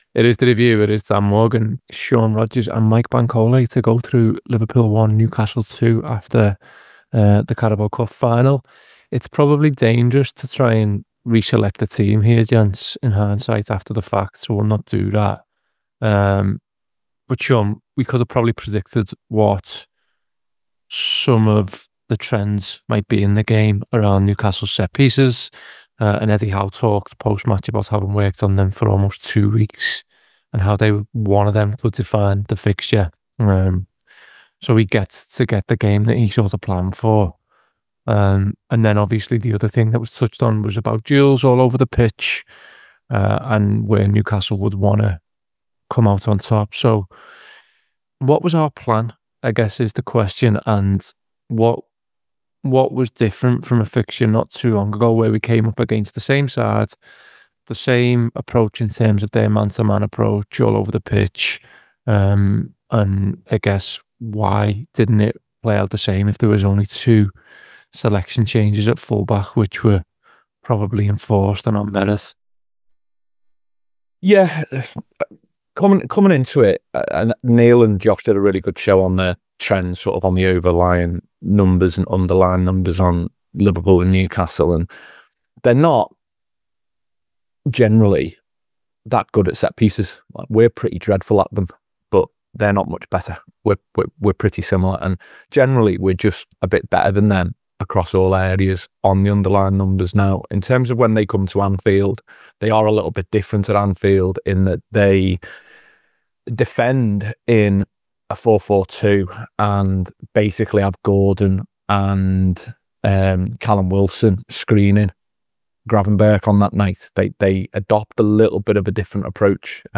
Below is a clip from the show – subscribe to The Anfield Wrap for more review chat around Liverpool 1 Newcastle United 2…